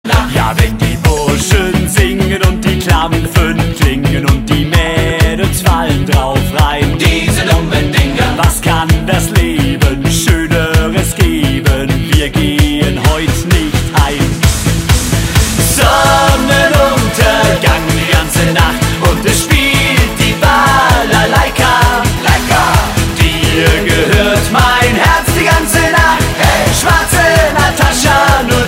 Gattung: Moderner Einzeltitel
Besetzung: Blasorchester
Der Party-Knaller für die aktuelle Saison